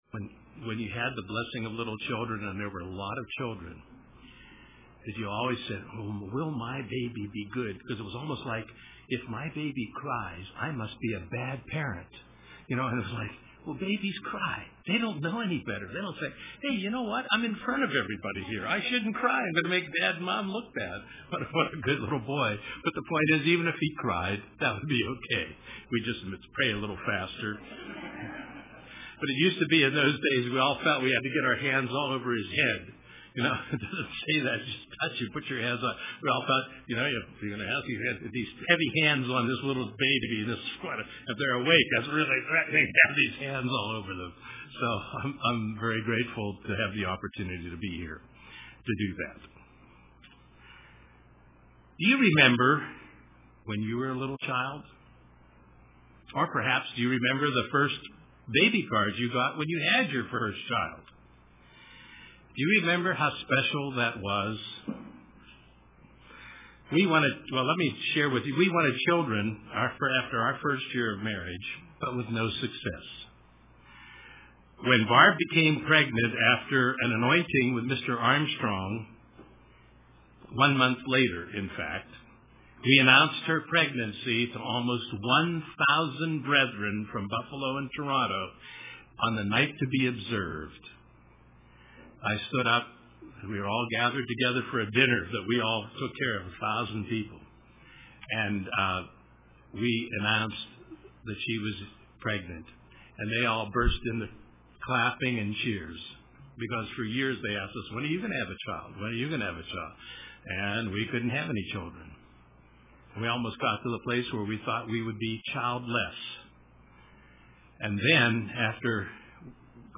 Children are a blessing that God gives. 02/04/2013 UCG Sermon Transcript This transcript was generated by AI and may contain errors.